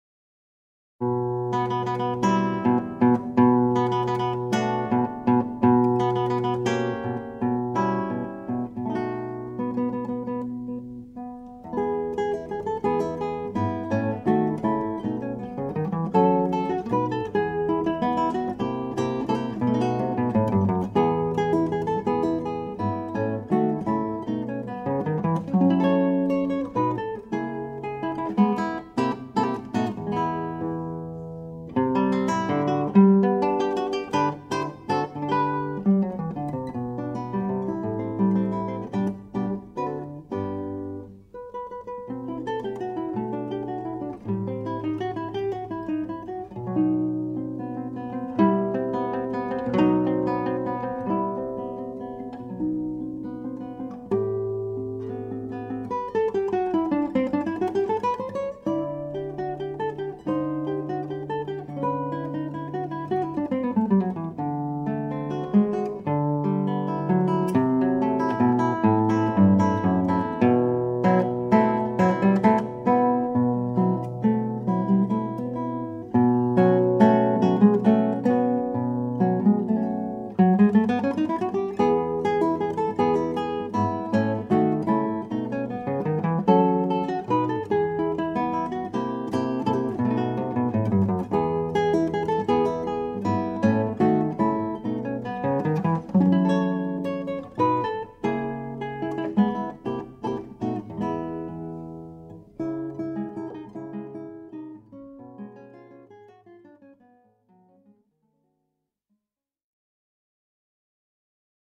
Guitarra.mp3